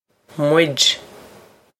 muid mwidge
This is an approximate phonetic pronunciation of the phrase.